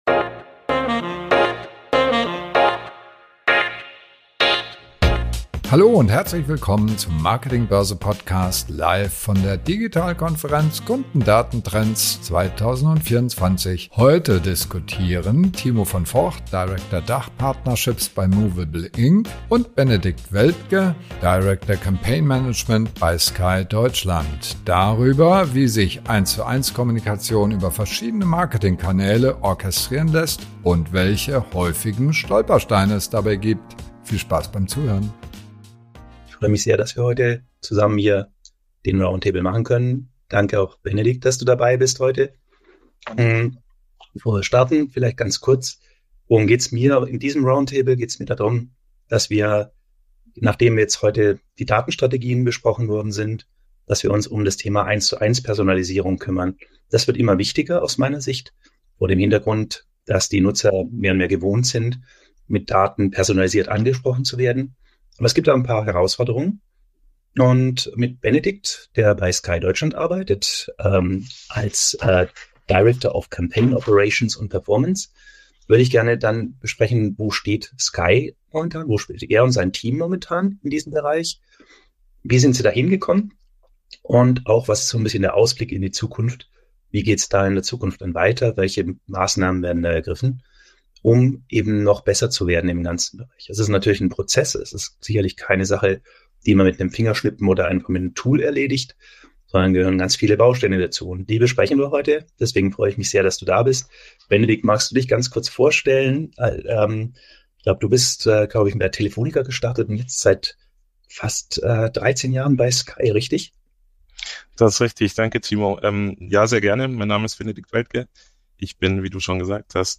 Roundtable